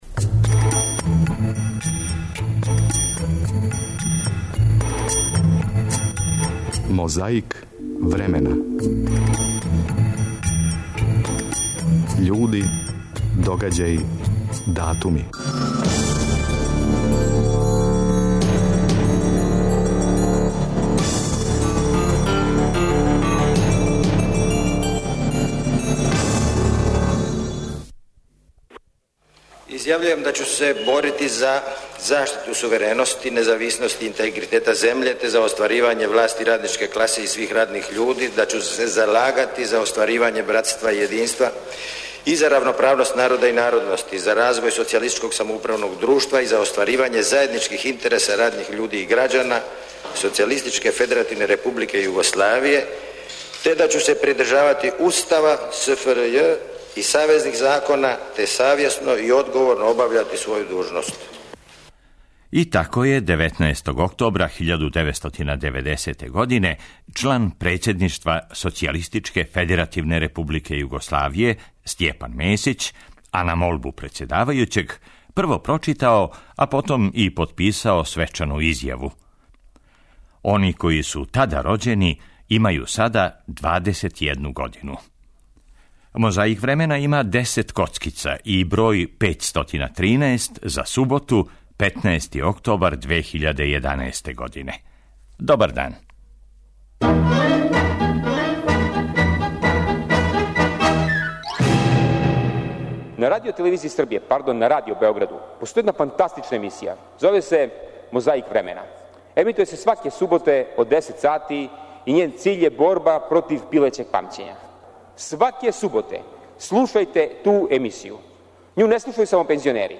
Музеј Николе Тесле отворен је 20. октобра 1955.године. Коцкице из тонског архива Радио Београда помажу нам да се сетимо Октобарске револуције у Русији.
Подсећа на прошлост (културну, историјску, политичку, спортску и сваку другу) уз помоћ материјала из Тонског архива, Документације и библиотеке Радио Београда.